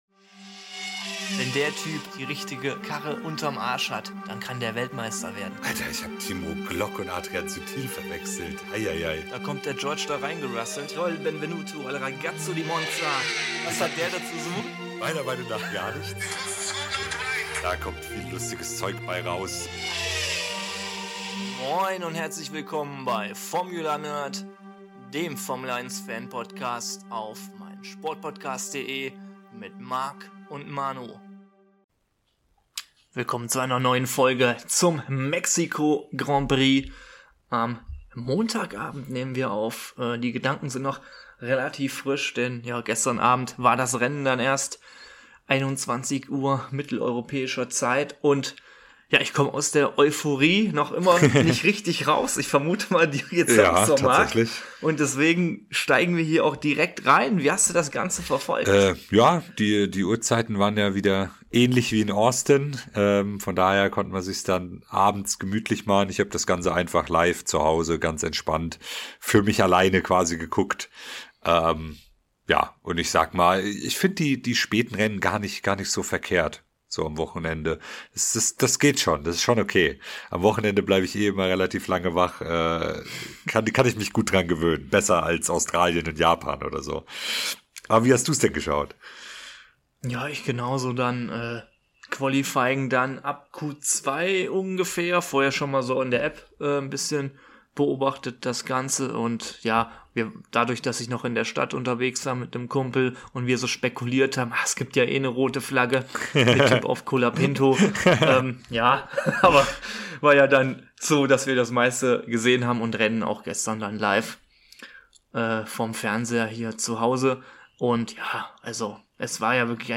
Formula Nerd ist der Fanpodcast zweier verrückter Formel 1 Freunde, die euch an Themen rund um das Rennwochenende, Gaming, F1 Fantasy und popkulturellen Ereignissen rund um die Formel 1 teilhaben lassen.